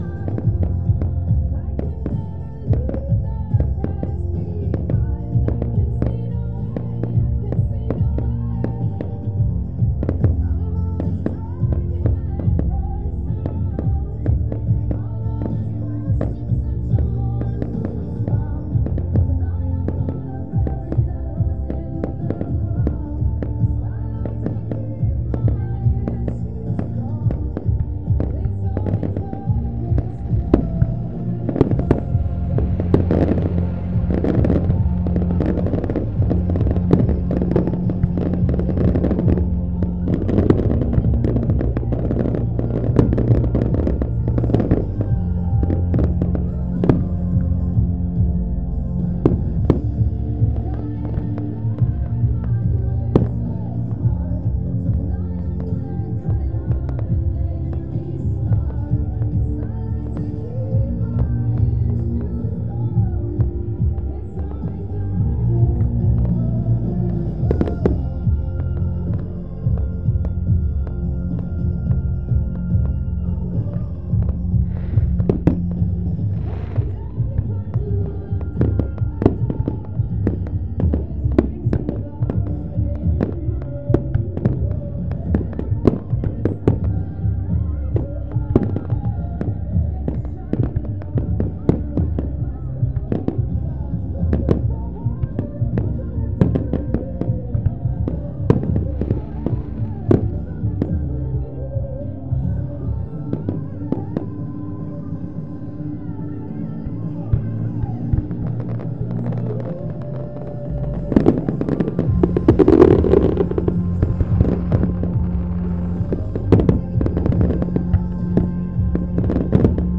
Firework Championship Team 3. End of display.